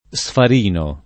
sfarino [ S far & no ]